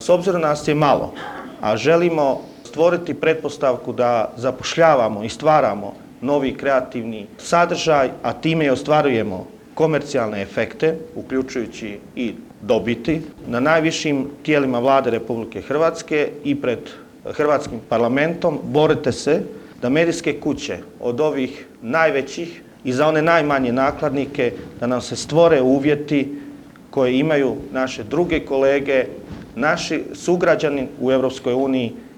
ZAGREB - Jučer je održan je prvi CROATIA MEDIA CONGRESS u organizaciji AGI-ja na temu izmjena Zakona o elektroničkim medijima.